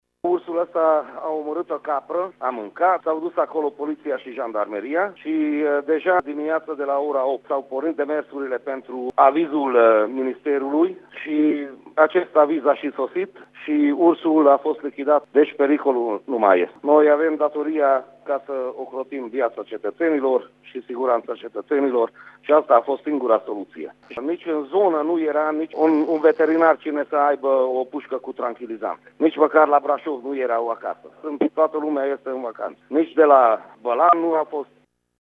Unul dintre motivele pentru care autoritățile au recurs la această măsură este că în județele învecinate nu a fost găsit niciun veterinar care să poată tranchiliza animalul, a declarat pentru Radio Tg.Mures viceprimarul din M.Ciuc, Fuleki Zoltan:
viceprimar.mp3